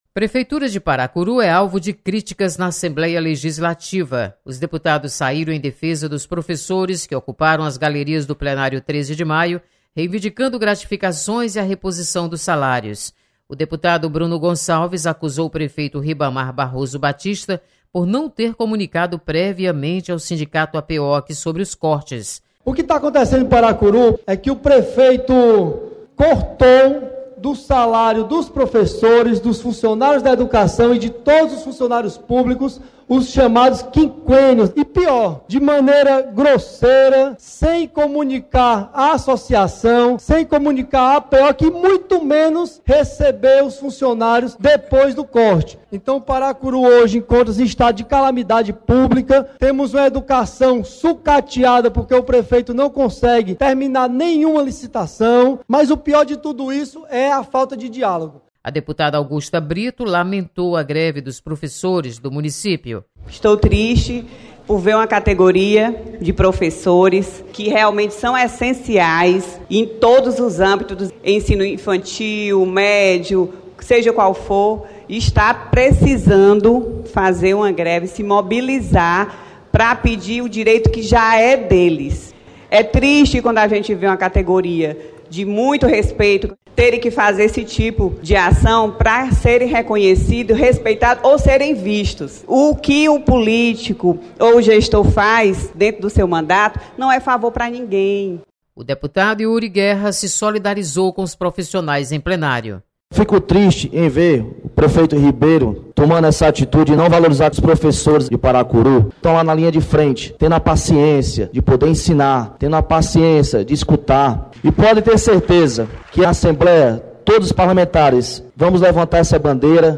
Deputados defendem direitos dos professores de Paracuru. Repórter